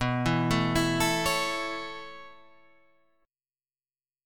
B9sus4 chord